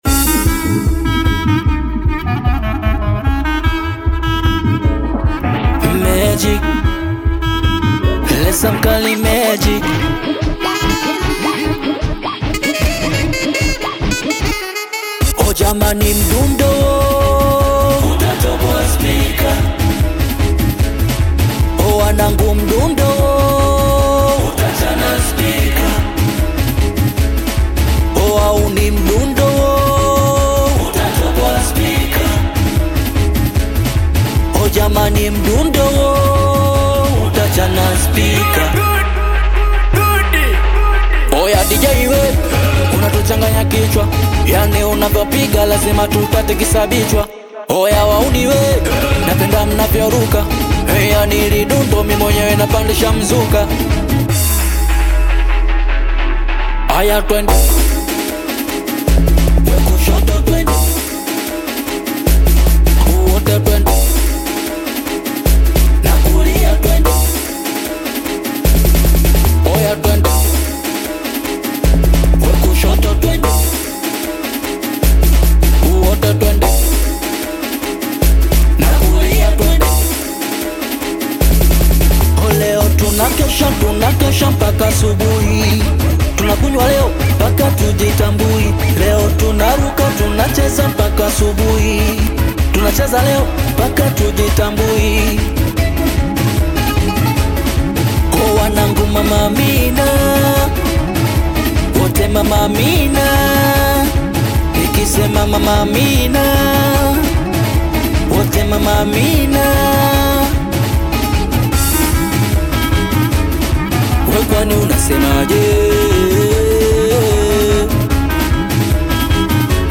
Audio Bongo flava Latest